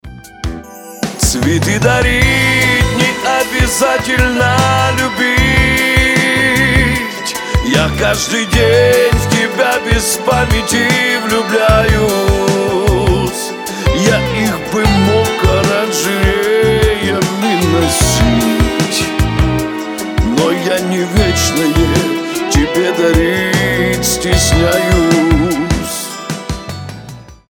шансон , романтические
медленные